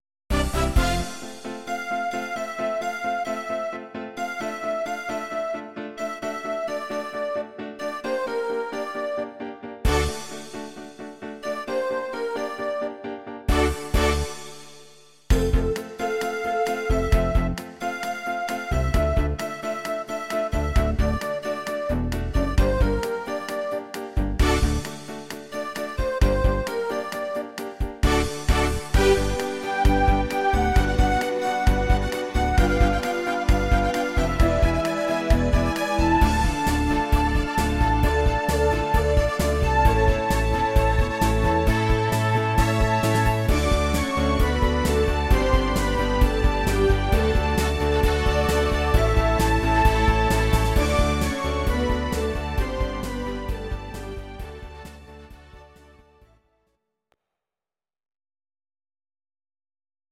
Audio Recordings based on Midi-files
Ital/French/Span, 2000s